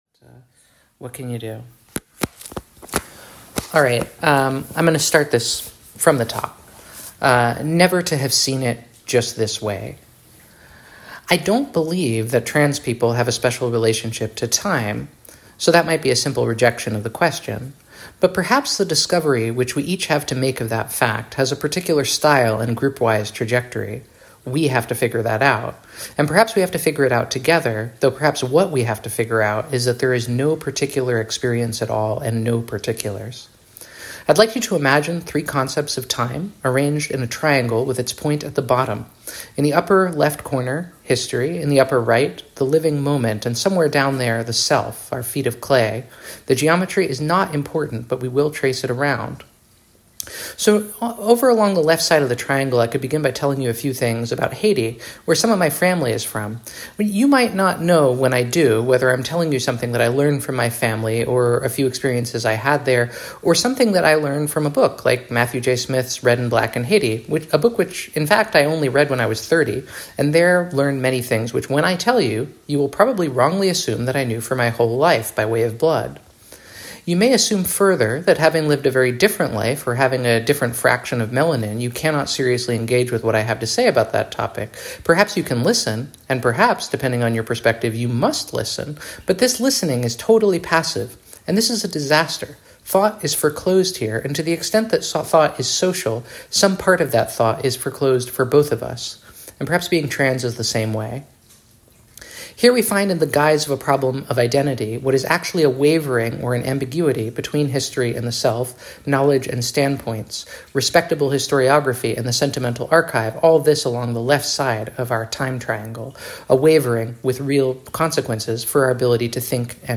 The event wasn't recorded, but I made an audio recording of my part on my phone which hopefully will attach to this message. Sort of self-indulgent freestyle philosophizing but it's also what I think.